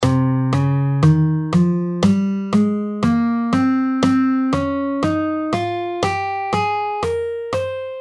C Phrygian
C Phrygian: C – D♭ – E♭ – F – G – A♭ – B♭ – C. A mode with a distinct Spanish flair, marked by its minor second.
C-Phrygian-3rd-Mode-Of-C-Major.mp3